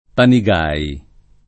[ pani g# i ]